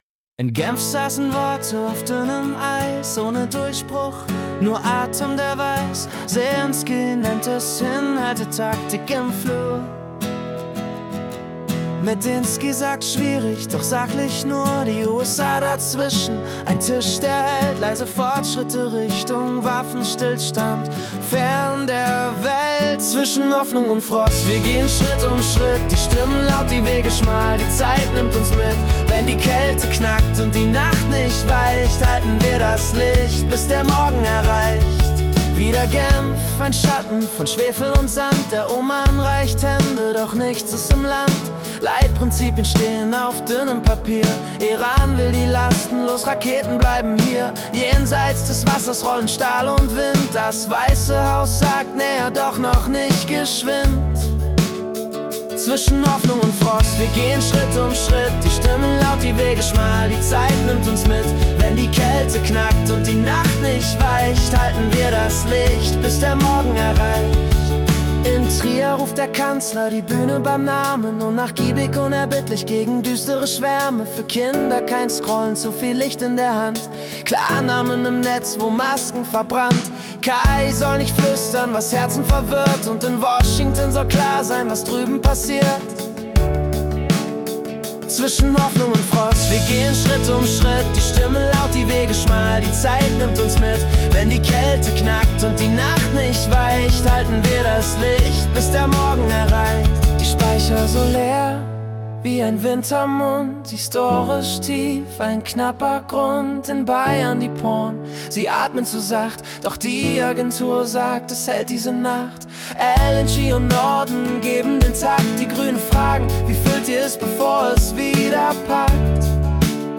Die Nachrichten vom 20. Februar 2026 als Singer-Songwriter-Song interpretiert.